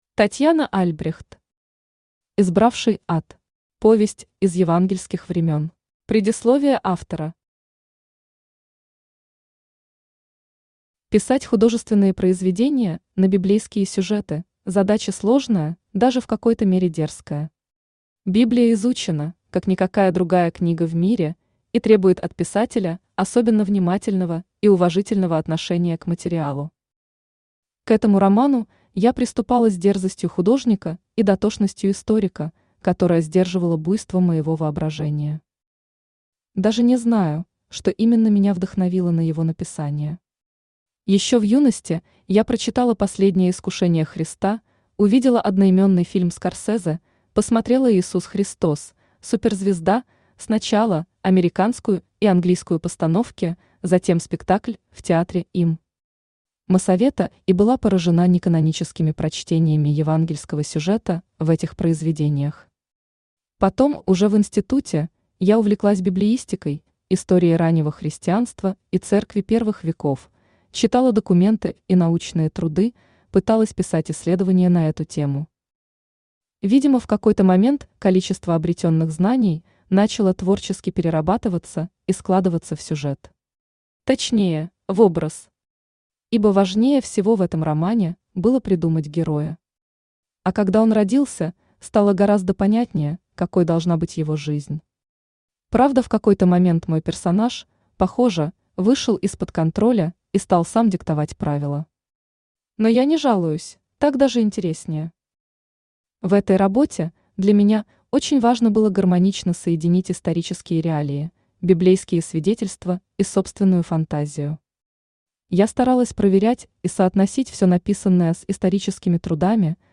Аудиокнига Избравший ад. Повесть из евангельских времен | Библиотека аудиокниг
Aудиокнига Избравший ад. Повесть из евангельских времен Автор Татьяна Альбрехт Читает аудиокнигу Авточтец ЛитРес.